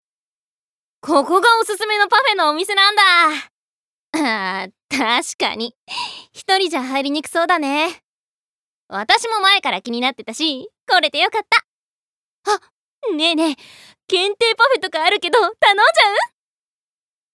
Voice Sample
セリフ３